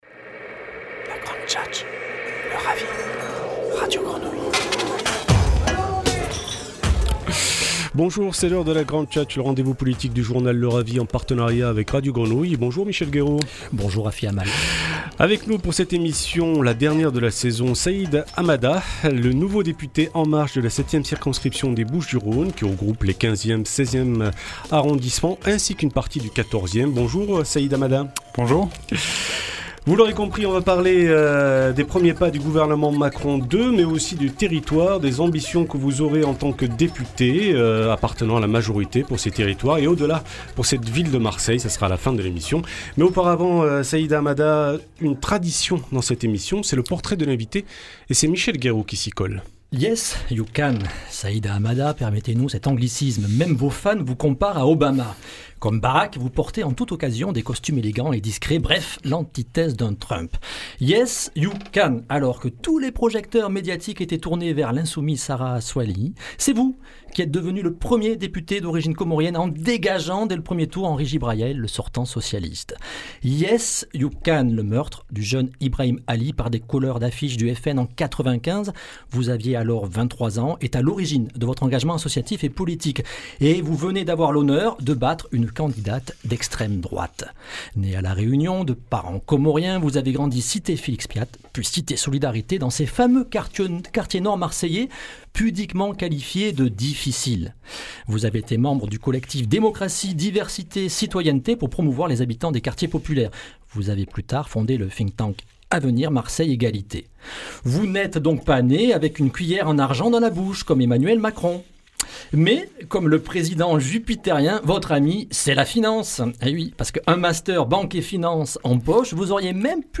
Entretien radio en partenariat avec Radio Grenouille